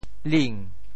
“輦”字用潮州话怎么说？
辇（輦） 部首拼音 部首 車 总笔划 15 部外笔划 8 普通话 niǎn 潮州发音 潮州 ling2 文 中文解释 辇 <名> (会意。